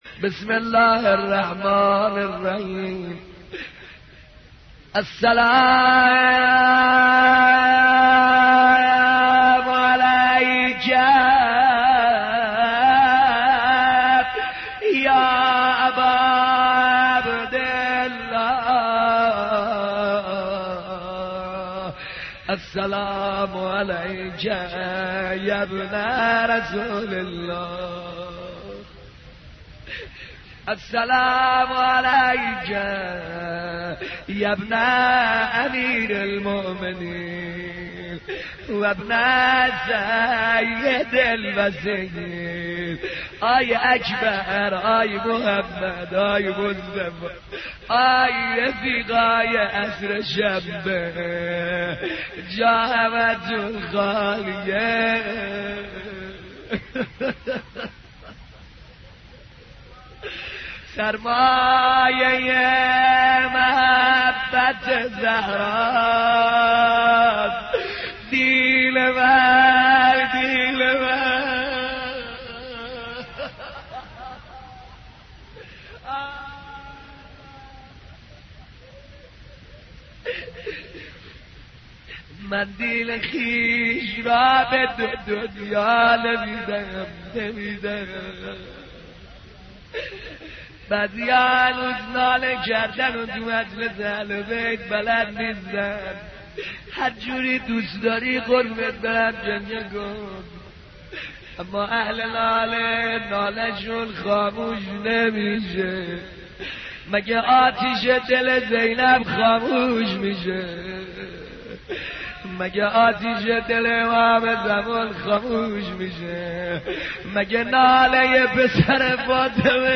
صوت | زیارت عاشورا با نوای حاج سعید حدادیان